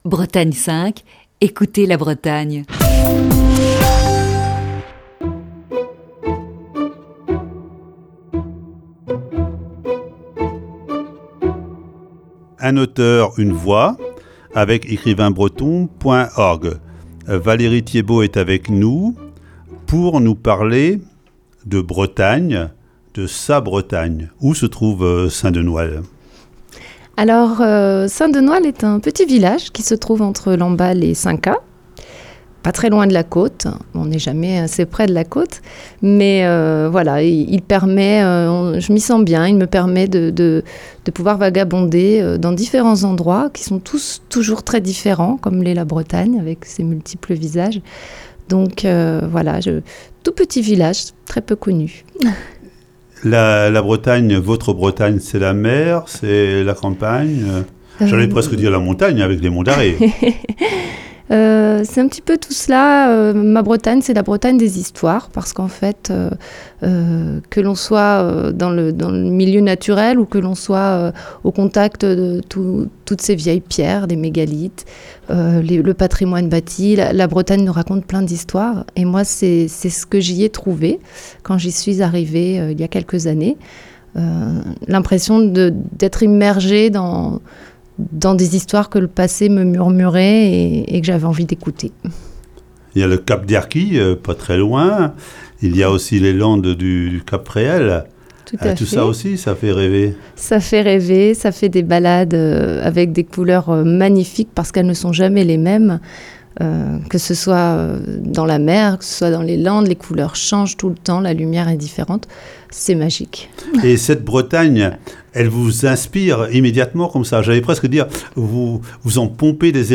Chronique du 14 janvier 2020.